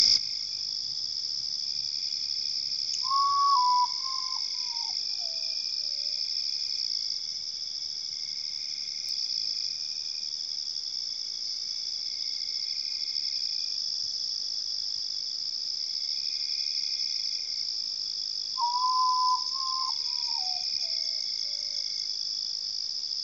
Common Potoo (Nyctibius griseus)
Condition: Wild
Certainty: Recorded vocal